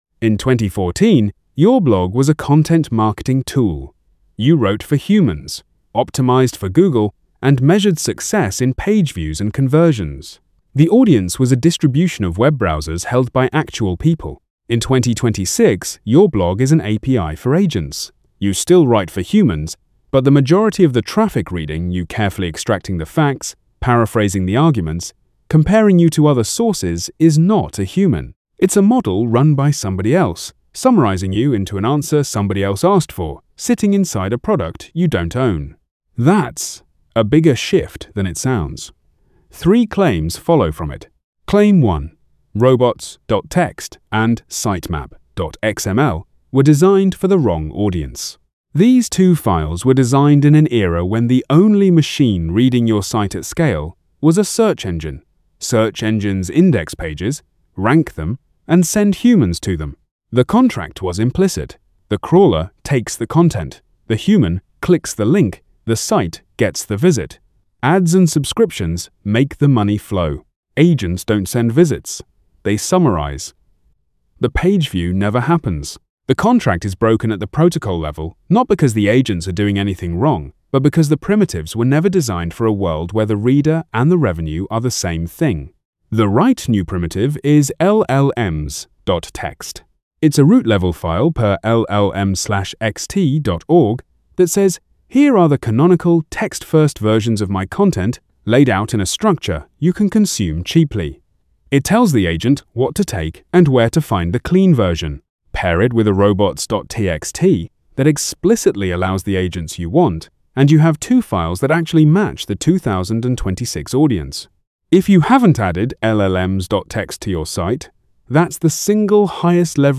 AI-narrated with MiniMax speech-2.8-hd · 7:25.